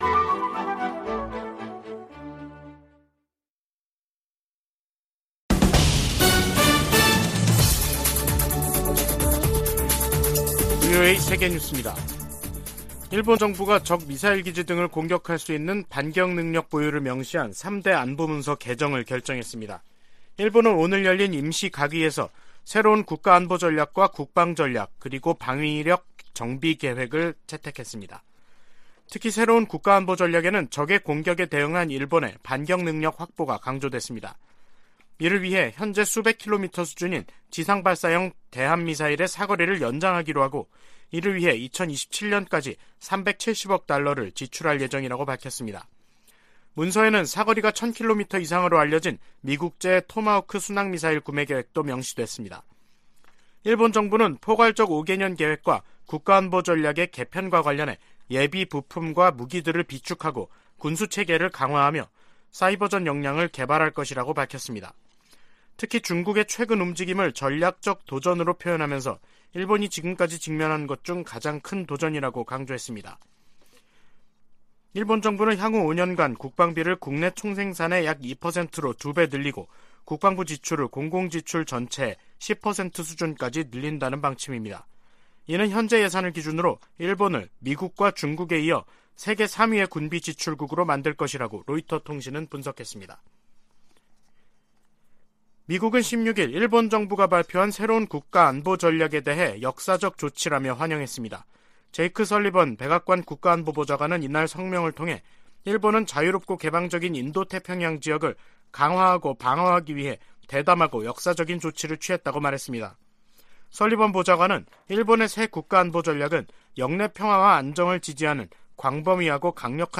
VOA 한국어 간판 뉴스 프로그램 '뉴스 투데이', 2022년 12월 15일 3부 방송입니다. 북한이 대륙간탄도미사일로 보이는 고출력 고체엔진 시험에 성공했다고 주장했습니다.